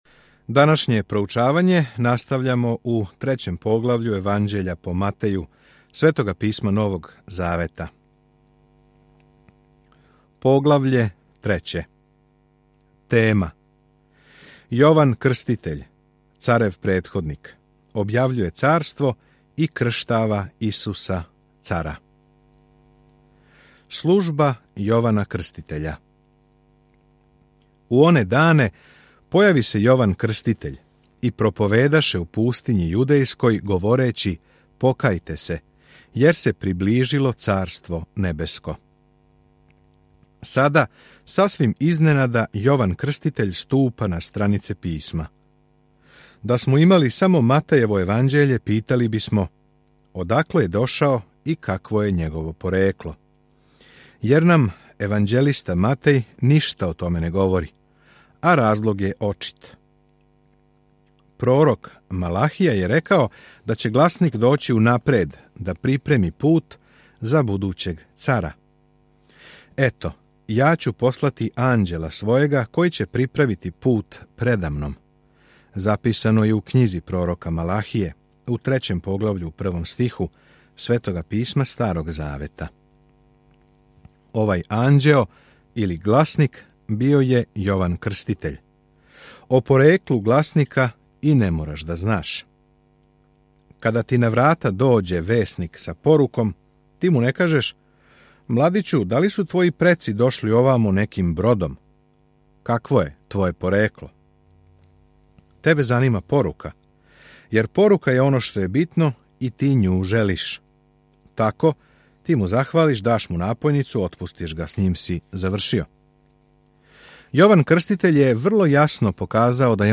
Sveto Pismo Matej 3 Dan 5 Započni ovaj plan Dan 7 O ovom planu Матеј доказује јеврејским читаоцима добру вест да је Исус њихов Месија показујући како су Његов живот и служба испунили старозаветно пророчанство. Свакодневно путујте кроз Матеја док слушате аудио студију и читате одабране стихове из Божје речи.